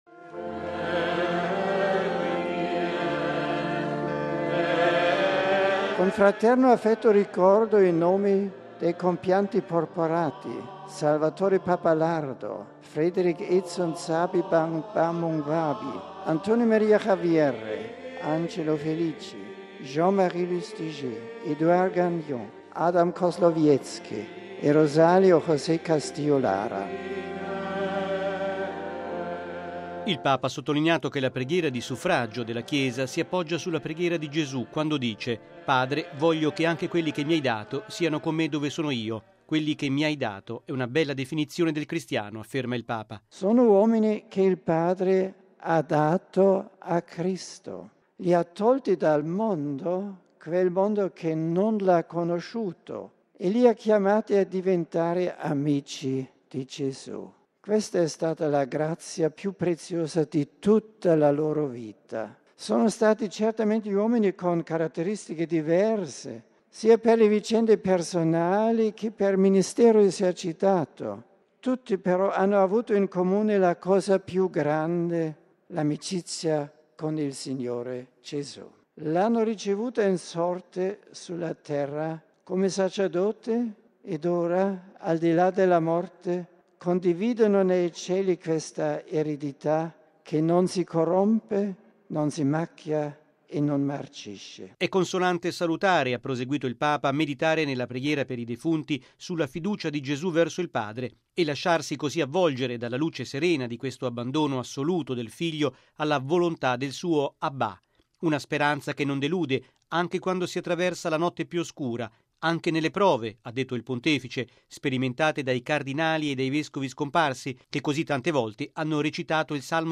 E’ quanto ha detto Benedetto XVI durante la Messa da lui presieduta questa mattina nella Basilica Vaticana in suffragio dei cardinali e vescovi defunti nel corso dell'anno.